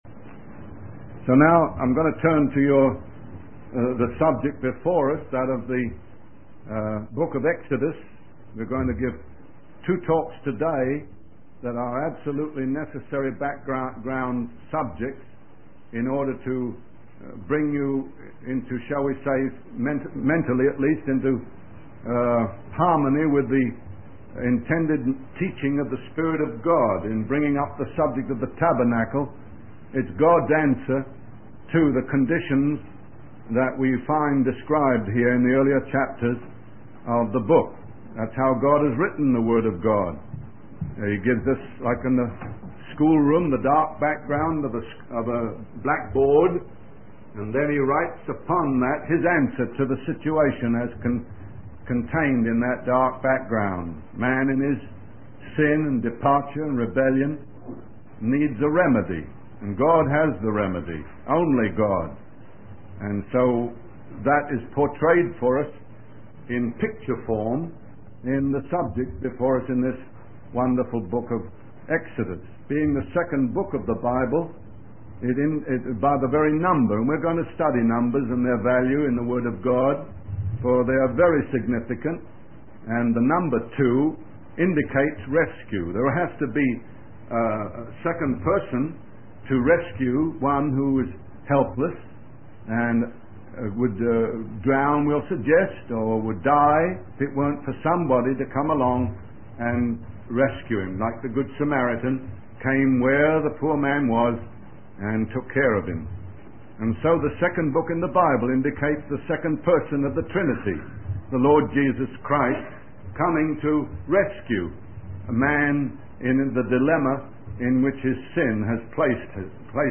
In this sermon, the preacher discusses the role of Jesus as the last Adam and his victory over sin and Satan.